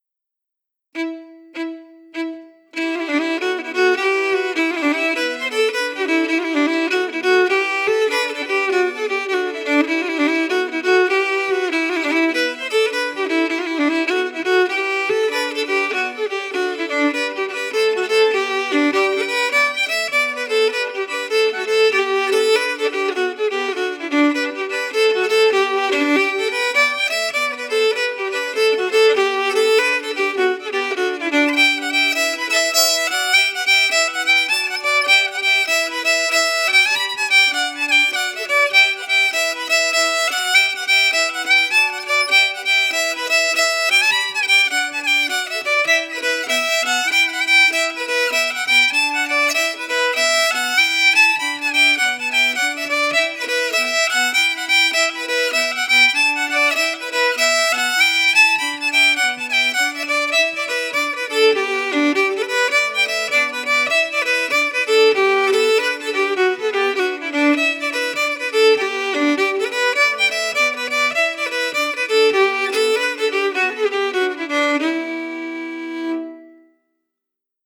Key: Em
Form: slip Jig
Melody emphasis
M: 9/8
Genre/Style: Irish slip jig